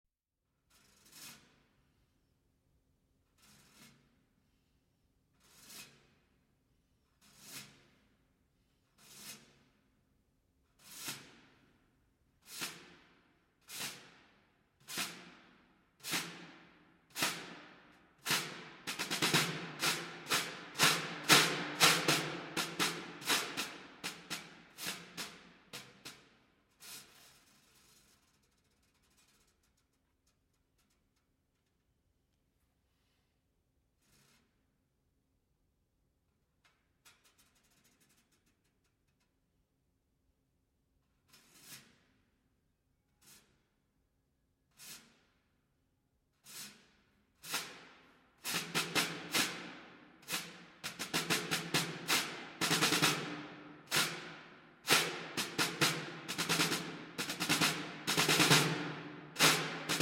Marimba
Vibraphone
Timpani